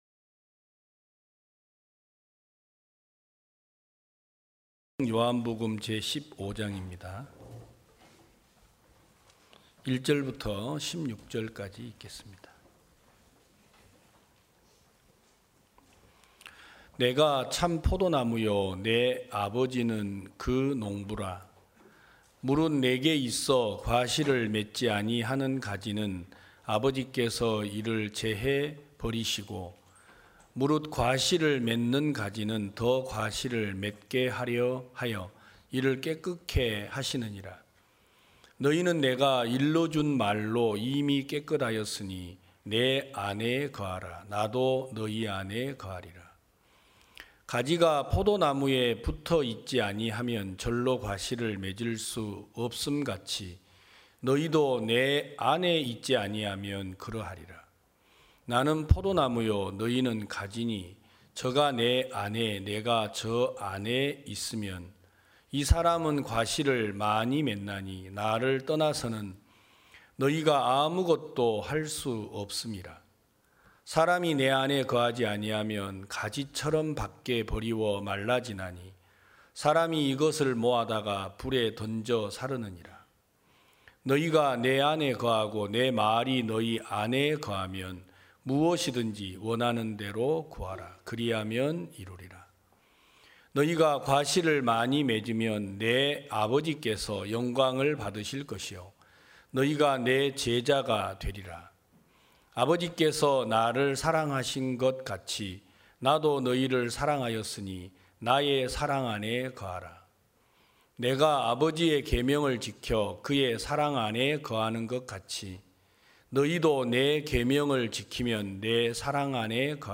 2023년 5월 21일 기쁜소식부산대연교회 주일오전예배
성도들이 모두 교회에 모여 말씀을 듣는 주일 예배의 설교는, 한 주간 우리 마음을 채웠던 생각을 내려두고 하나님의 말씀으로 가득 채우는 시간입니다.